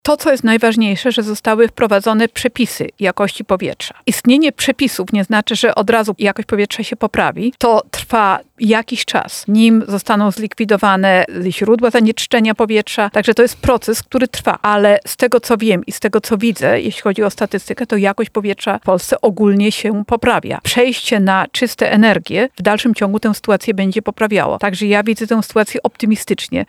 O tym, jaka jest szansa na poprawę jakości powietrza w naszym kraju, mówiła prof. Lidia Morawska podczas Porannej Rozmowy Radia Centrum: